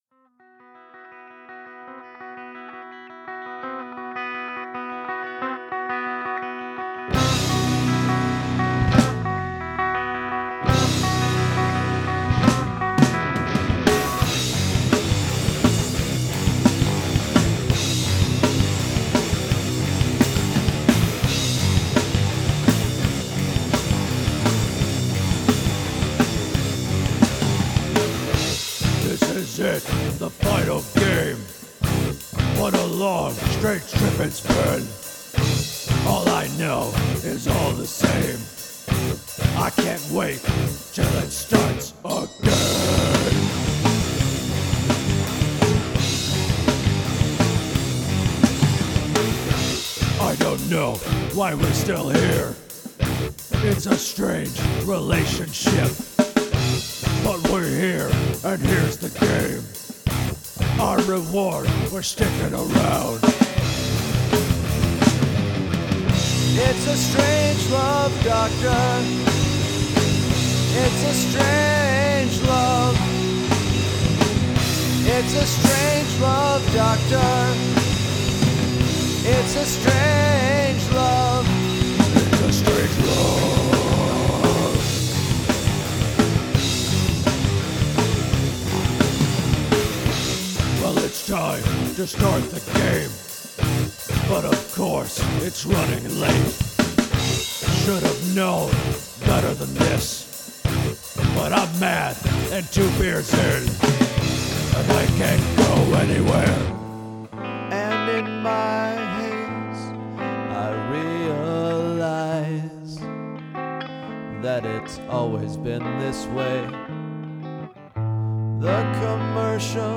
As a result, there is a LOT more going on here than usual… so much so that I think the song can be divided into three or four sections.
Look out for commentators, clean sections in an otherwise heavy tune, and the requisite end-of-season montage at the end, sending this track over the 9-minute mark.